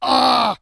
initiate_die3.wav